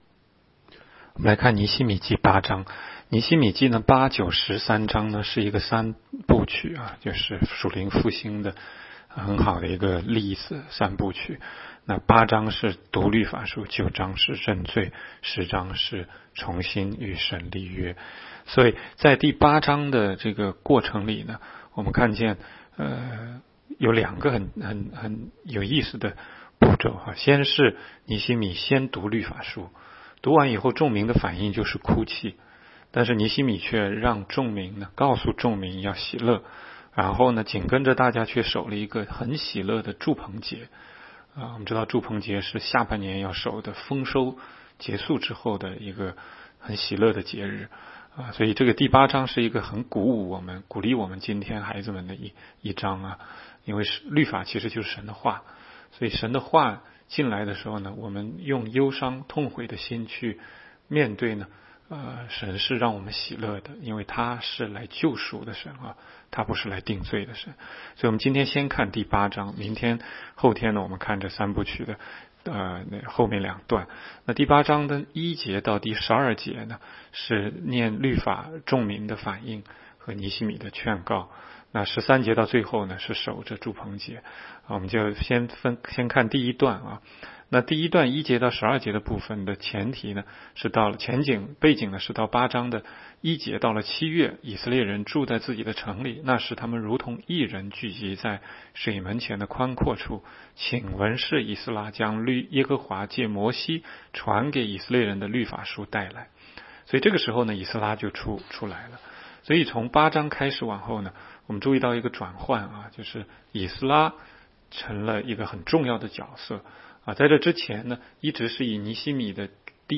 16街讲道录音 - 每日读经-《尼希米记》8章